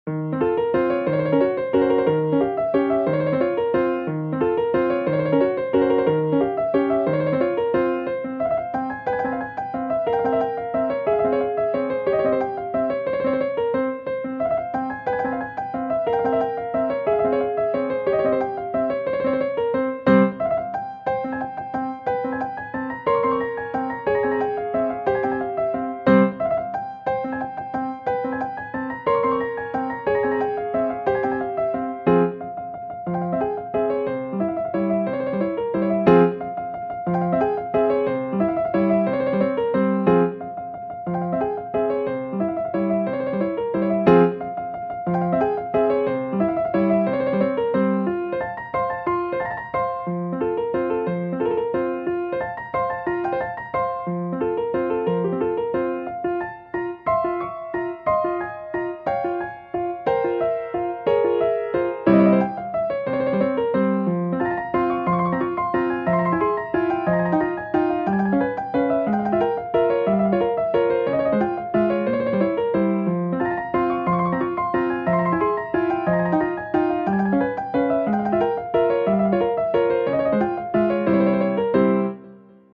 Müəllif: Azərbaycan Xalq Rəqsi